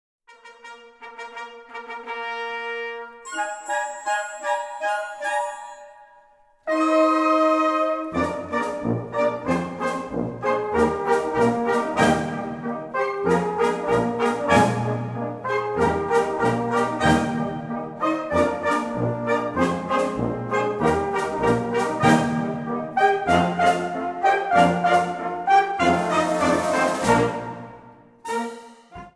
Gattung: Polka francaise
Besetzung: Blasorchester